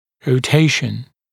[rəu’teɪʃn][роу’тейшн]ротация, поворот зуба вокруг продольной оси